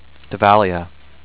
da-VAL-ee-uh